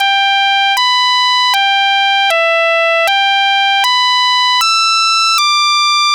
Track 07 - Synth 01.wav